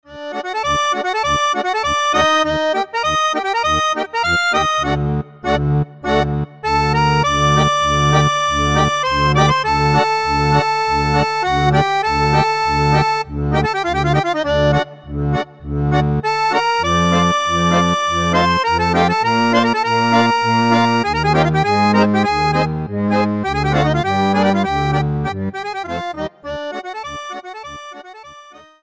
Partitura Acordeón
Acordeón con cifrado de acordes
• Tono original: D